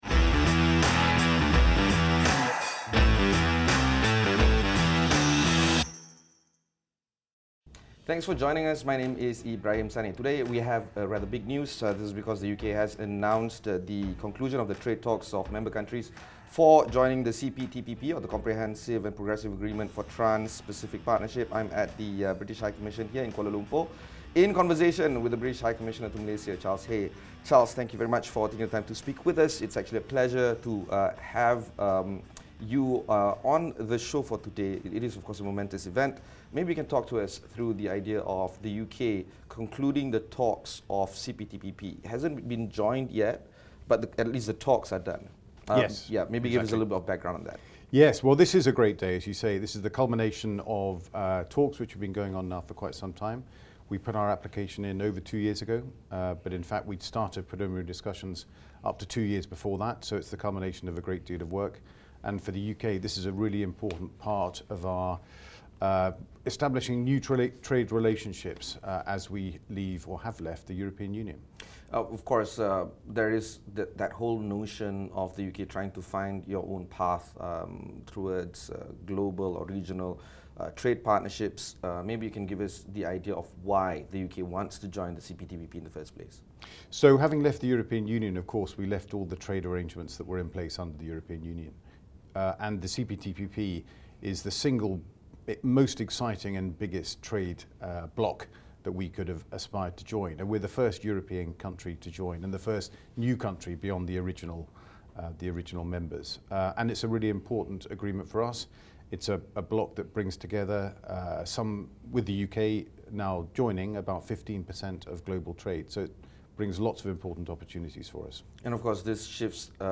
speaks with British High Commissioner to Malaysia, Charles Hay on how the UK's accession to the Comprehensive and Progressive Agreement for Trans-Pacific Partnership (CPTPP) will boost the economies of these two nations.